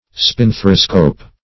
Spinthariscope \Spin*thar"i*scope\, n. [Gr. spinqari`s spark +